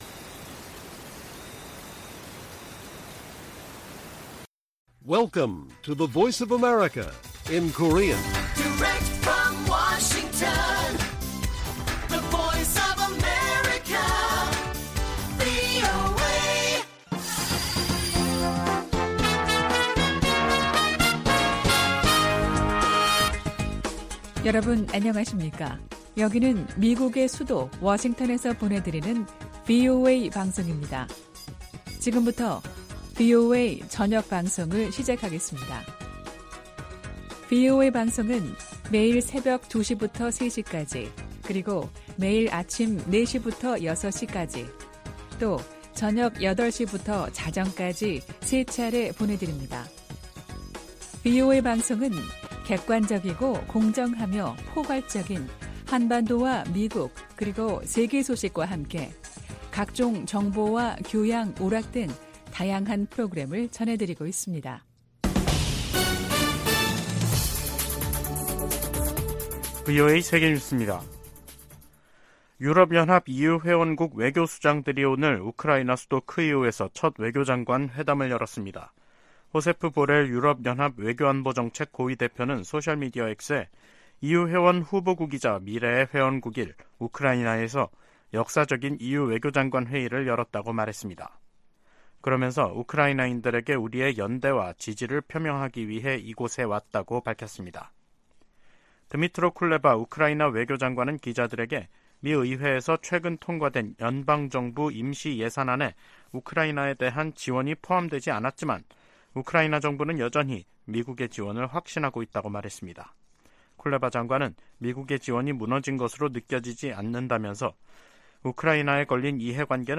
VOA 한국어 간판 뉴스 프로그램 '뉴스 투데이', 2023년 10월 2일 1부 방송입니다. 국제원자력기구(IAEA)는 오스트리아에서 열린 제67차 정기총회에서 북한의 지속적인 핵 개발을 규탄하고, 완전한 핵 폐기를 촉구하는 결의안을 채택했습니다. 북한이 핵 보유국 지위를 부정하는 국제사회 비난 담화를 잇달아 내놓고 있습니다.